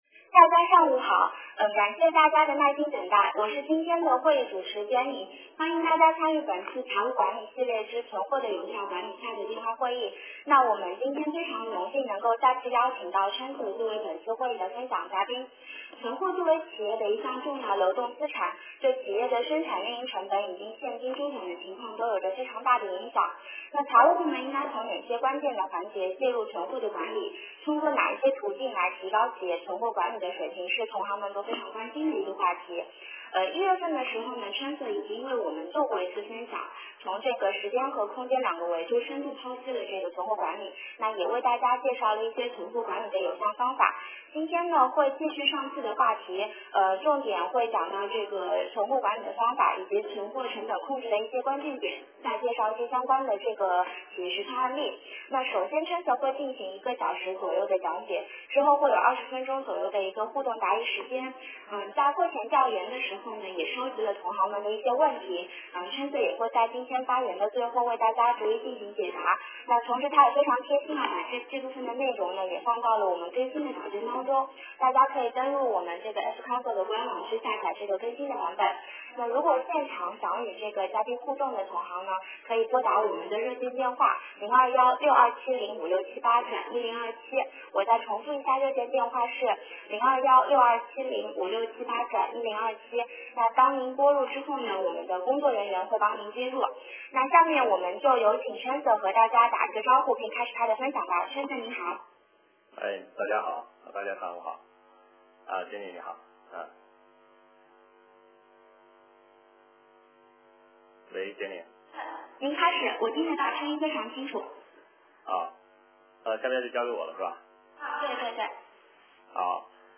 Webinar 财务管理系列之存货的有效管理(下)
电话会议